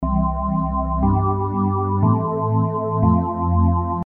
Similarly, the I-V-vi-IV progression is commonly found in many hit songs, providing a catchy and memorable sound.
The following audio chord progression examples are based on the assumption that the root note is C.
I-V-vi-IV.mp3